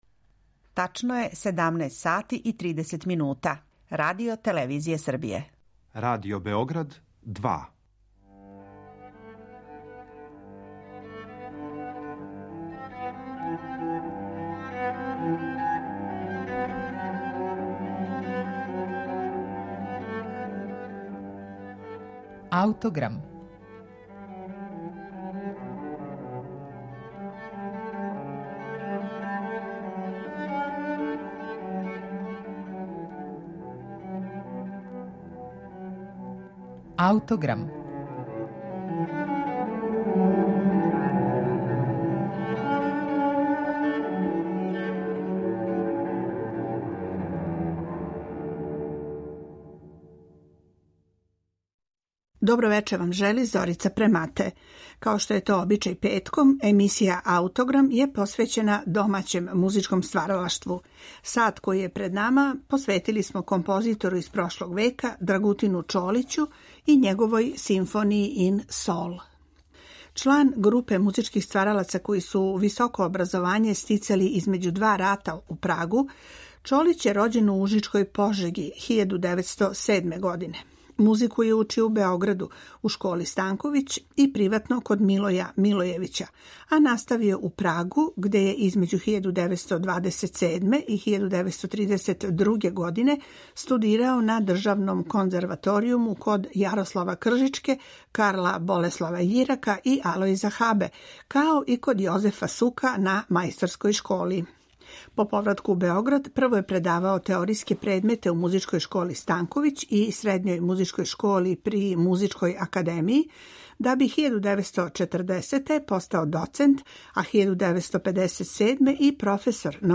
Емисију ће започети Чолићева „Симфонија ин сол", настала 1968. године, а на нашем архивском снимку ово дело изводе Београдска филхармонија и Живојин Здравковић.